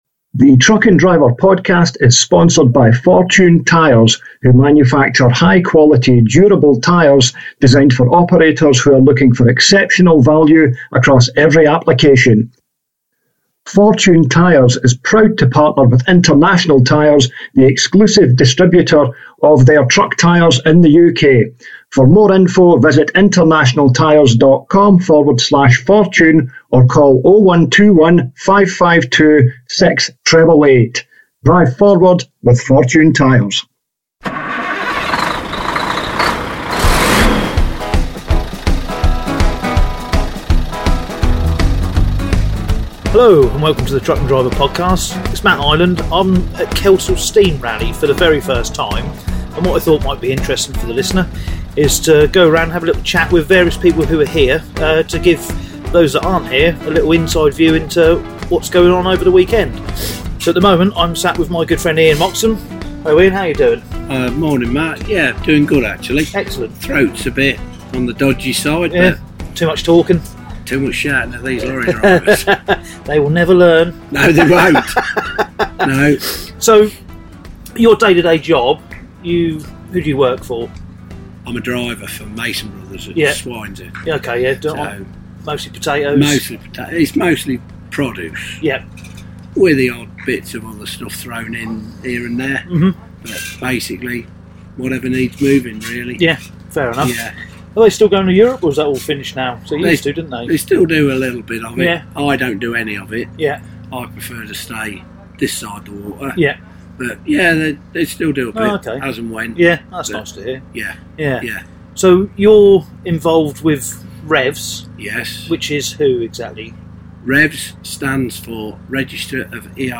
is joined by a selection of attendees (including a few familiar voices) from this year's Kendall Steam Rally on the Truck & Driver podcast, the regular podcast for lorry drivers.